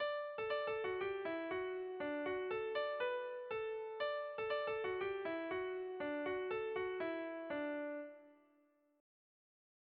Irrizkoa
A-A2